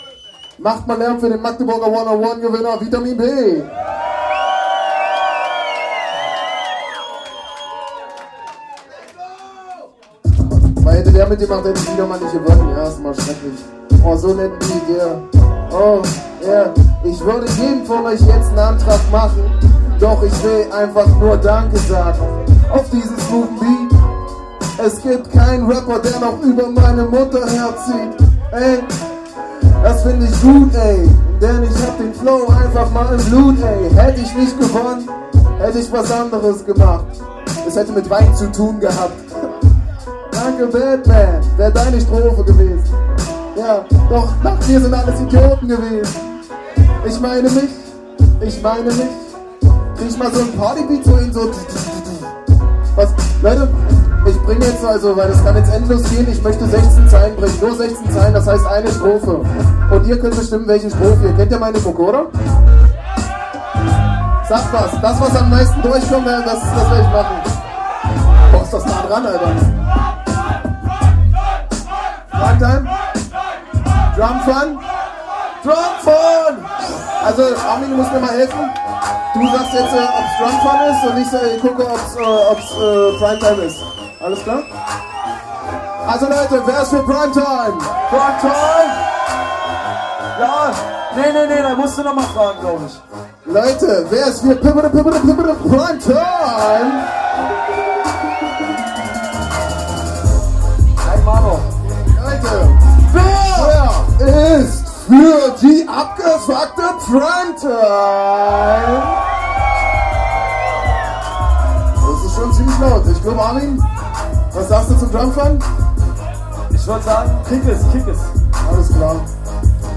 folgende MCs traten die Vorrunde an: